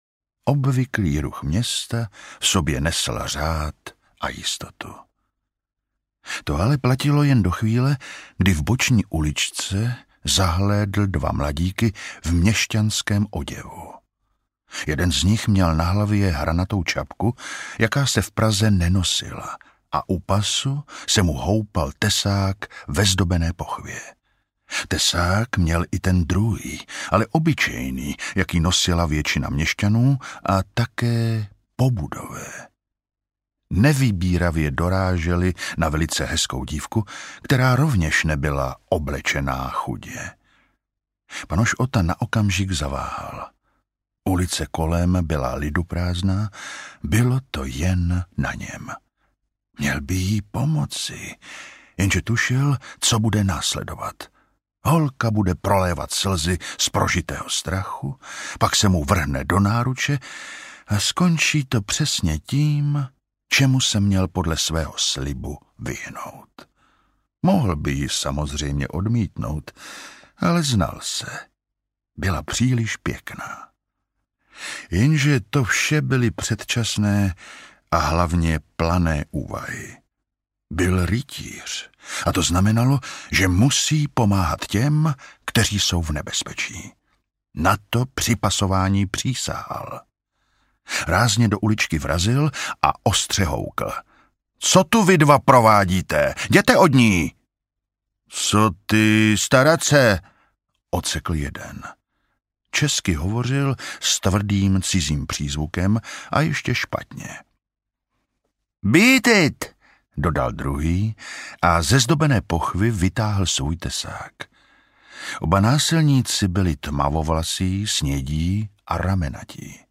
Morový testament audiokniha
Ukázka z knihy